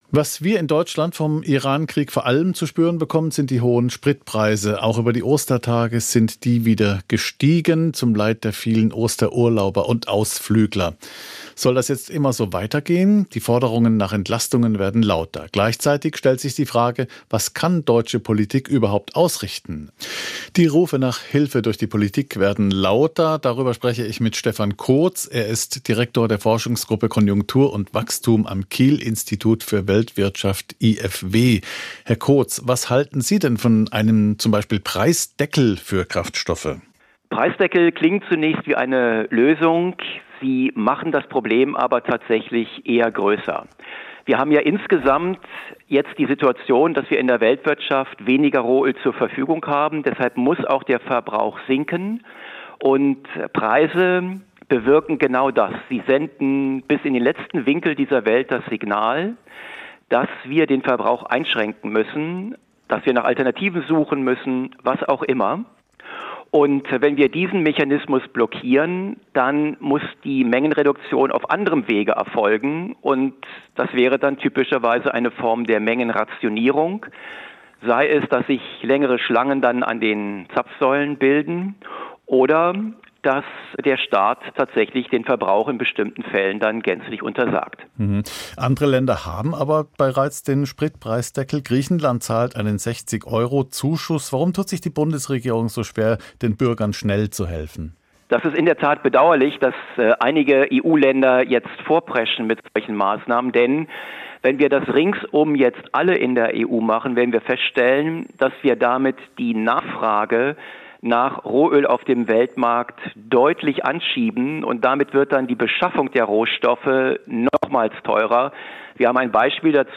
Interviews aus SWR Aktuell als Podcast: Im Gespräch